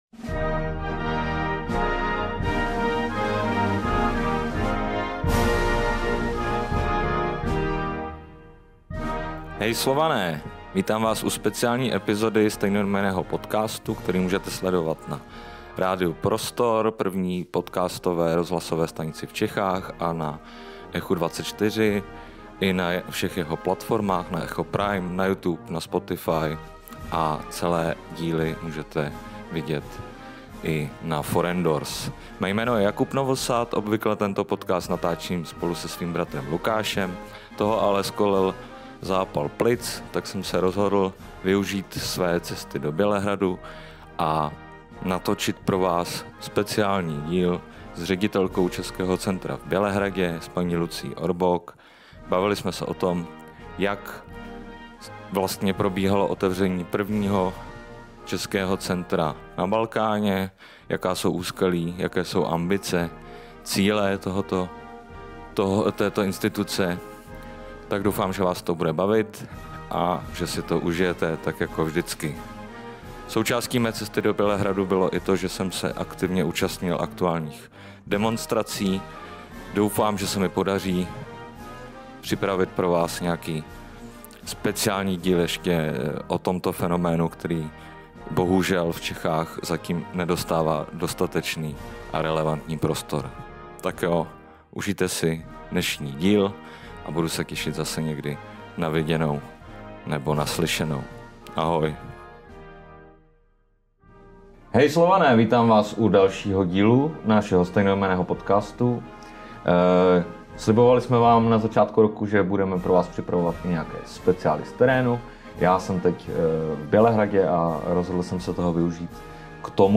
Speciál ze Srbska o vzniku a fungování jediného Českého centra na západním Balkáně.